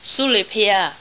Sule Pagoda (SOO-lay Pagoda) AIFF,